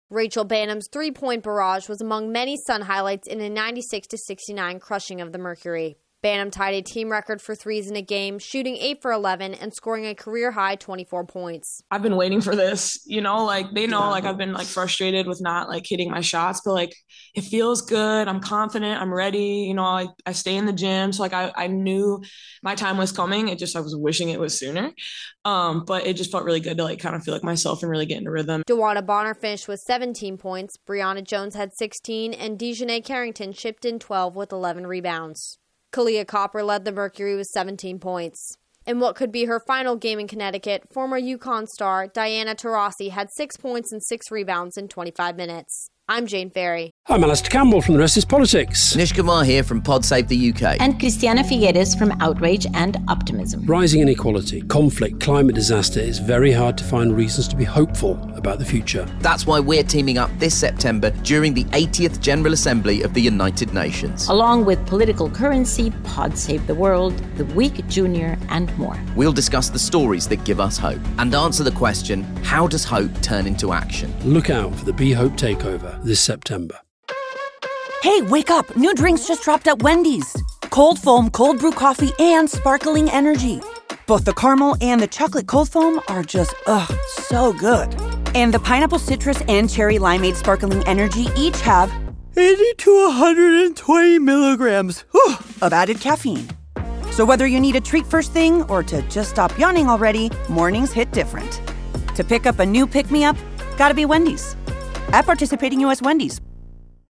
The Sun roll over the Mercury to move 1 1/2 games off the Eastern Conference lead. Correspondent